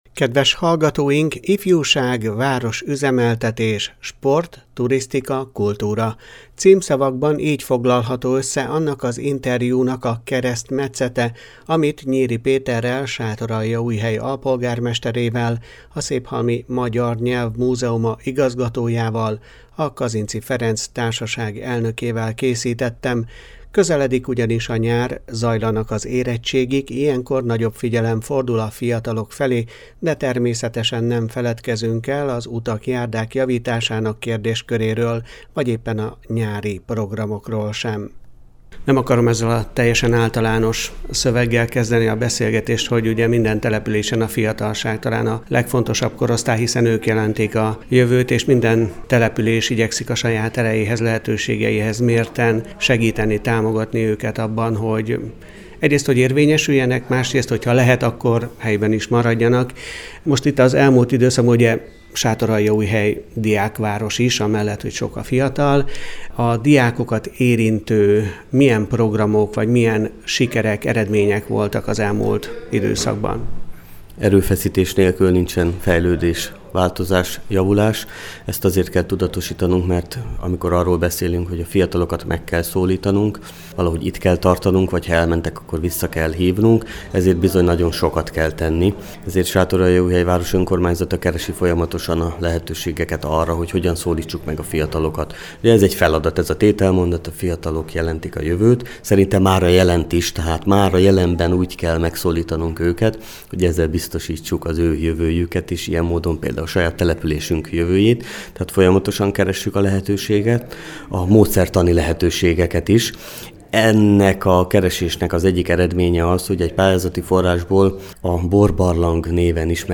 De természetesen nem feledkezünk el a sátoraljaújhelyi utak-járdák javításának kérdésköréről, vagy éppen a nyári programokról sem. Nyiri Péterrel, Sátoraljaújhely alpolgármesterével, a széphalmi Magyar Nyelv Múzeuma igazgatójával, a Kazinczy Ferenc Társaság elnökével beszélgetünk a város ifjúsági, városüzemeltetési, sport, turisztikai, valamint kulturális ügyeiről.